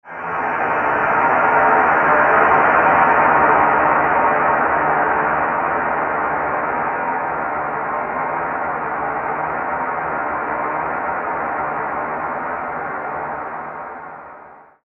Gemafreie Sounds: Wind und Sturm
mf_SE-3006-cold_synth.mp3